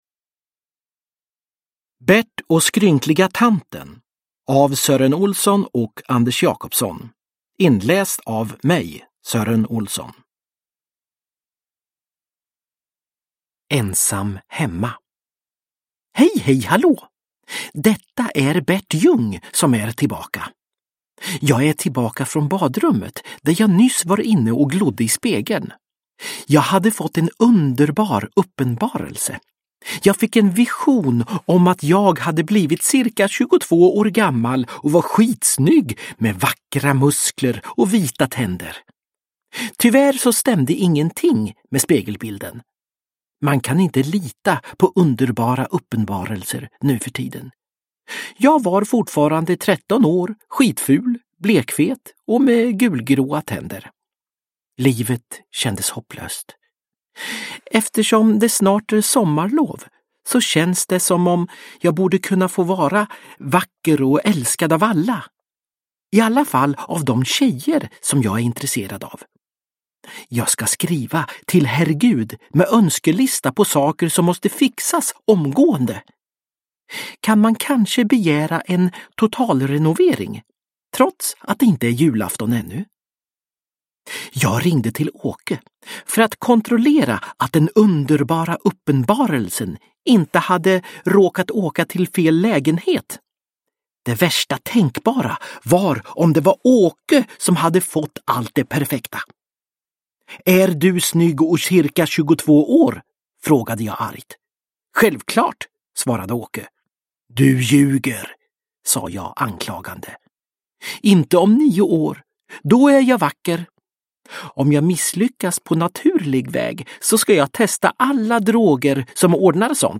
Bert och skrynkliga tanten – Ljudbok – Laddas ner
Uppläsare: Sören Olsson